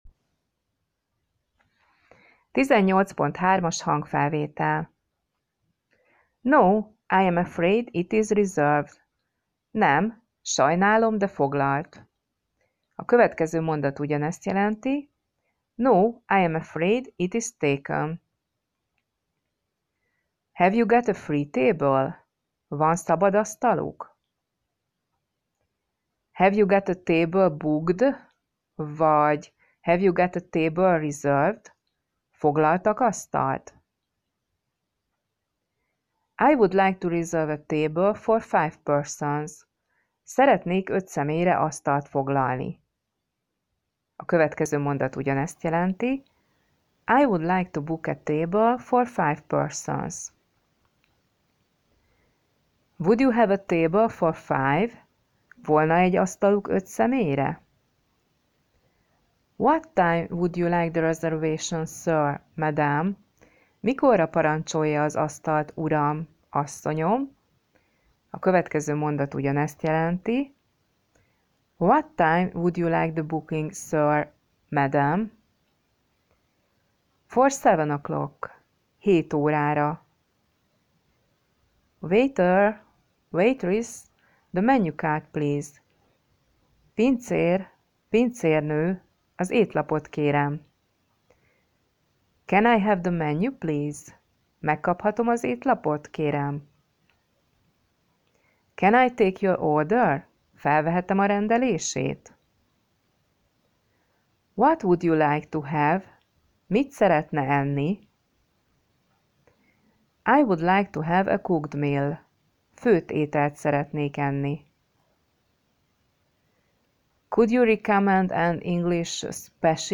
lassan, tagoltan, jól artikuláltan, kellemes hangon mondja el a szavakat, kifejezéseket, mondatokat